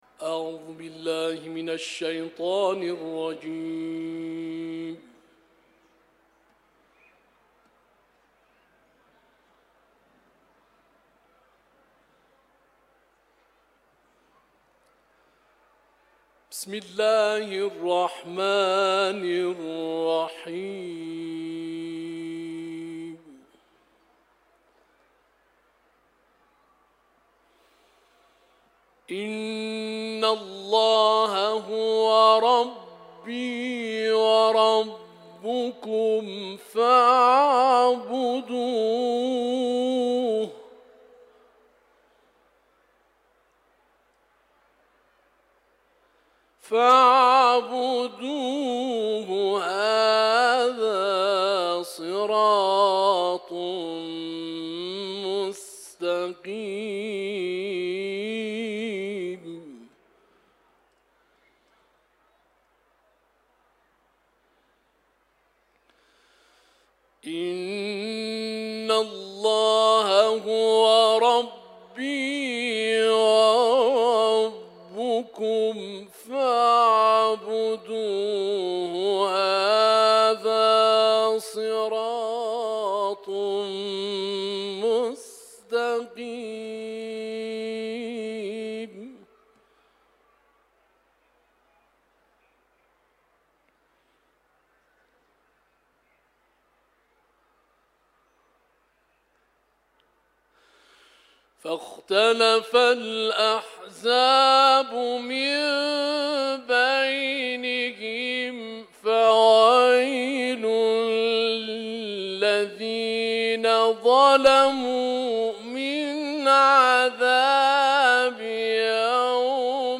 صوت تلاوت آیاتی 64 تا 73 سوره‌ « زخرف»
در حرم مطهر رضوی به اجرا رسیده